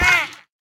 mob / dolphin / hurt1.ogg
hurt1.ogg